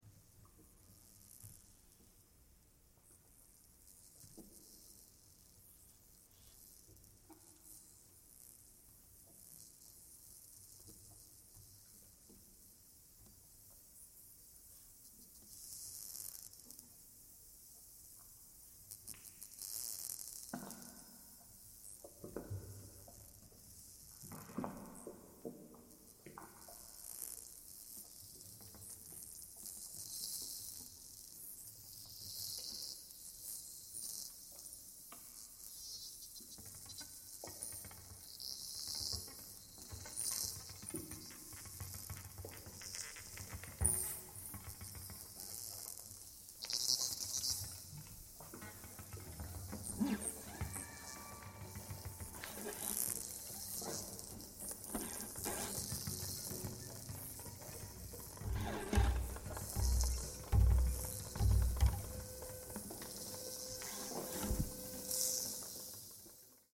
recorded October 1, 2024 in Jordan Hall, Boston